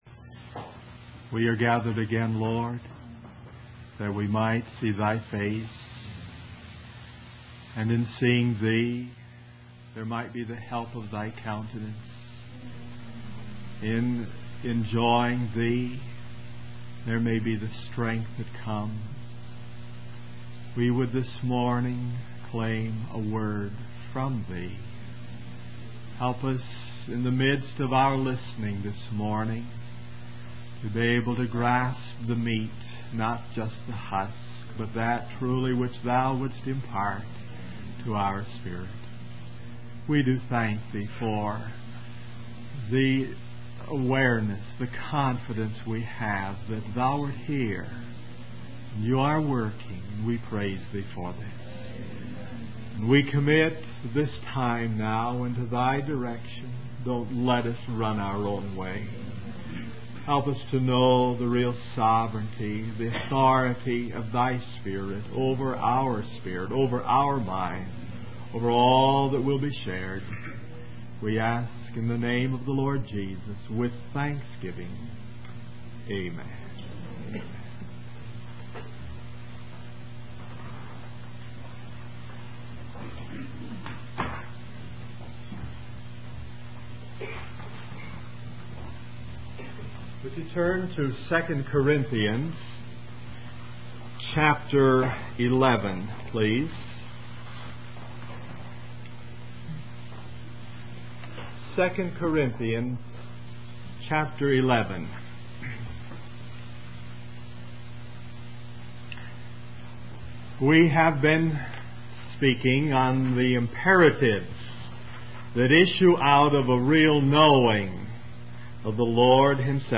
In this sermon, the speaker discusses the human desire for goals and purpose in life. He mentions how God has the right to give goals and reveals his purpose to individuals. The speaker highlights the danger of becoming consumed with reaching goals and the temptation to rely on philosophy rather than a genuine relationship with God.